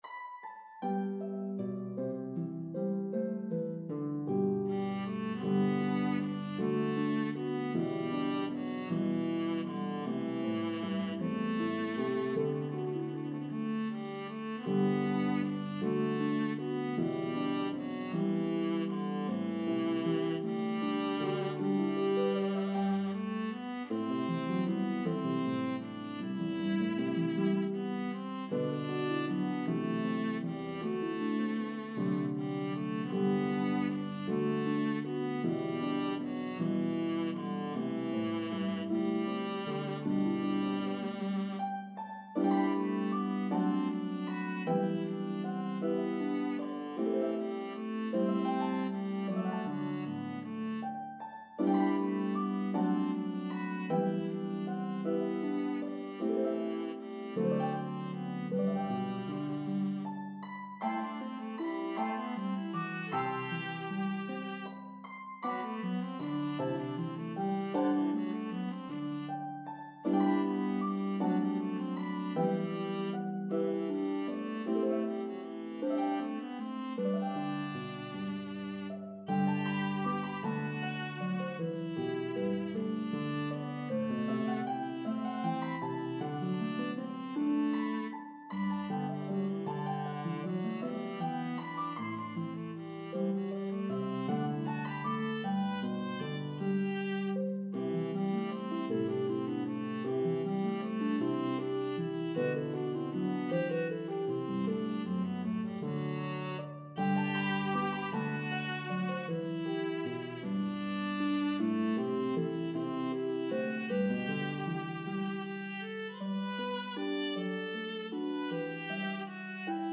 Harp and Viola version